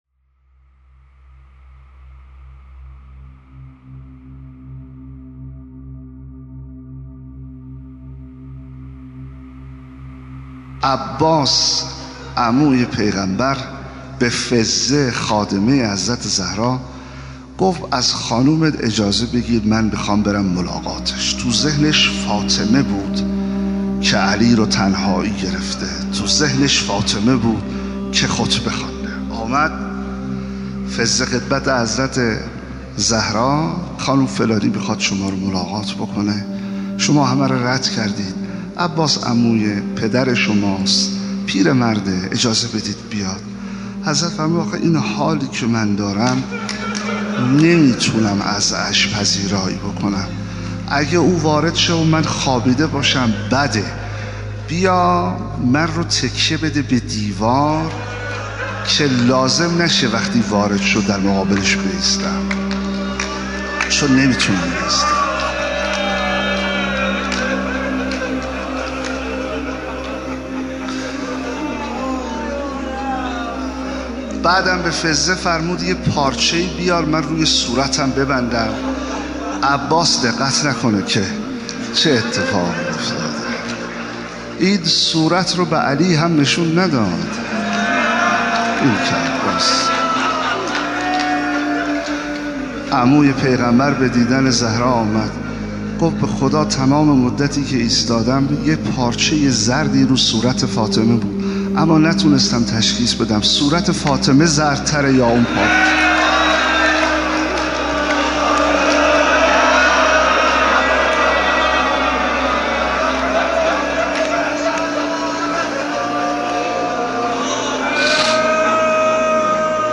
بخشی از سخنرانی